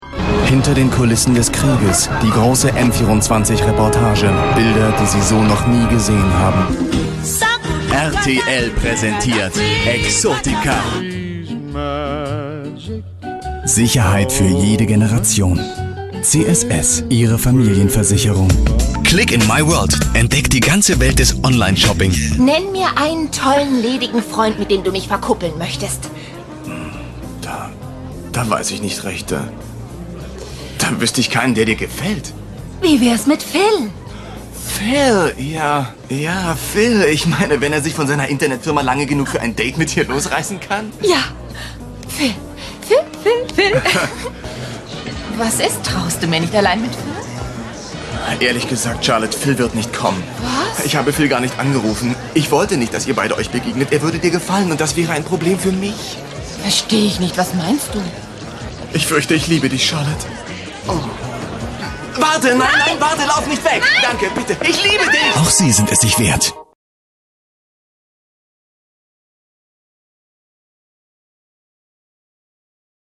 Deutscher Sprecher und Schauspieler.
Sprechprobe: Industrie (Muttersprache):